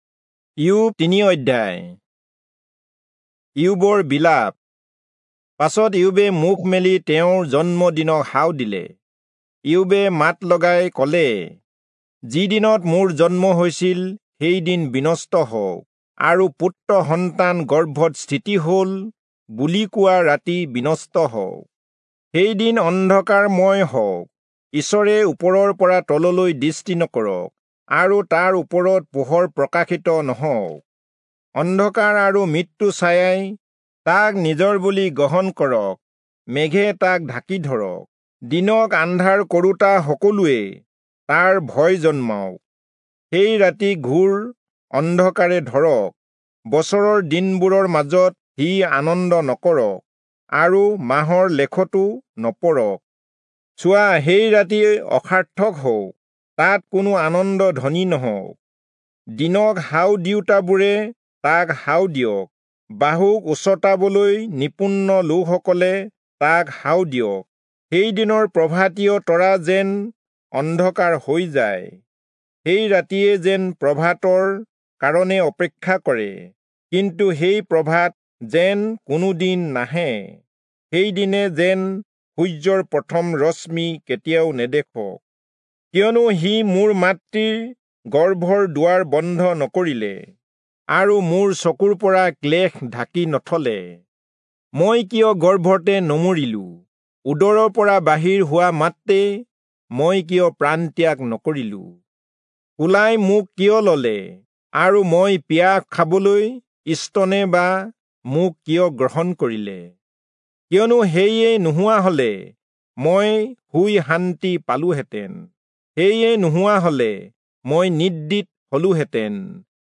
Assamese Audio Bible - Job 38 in Ervmr bible version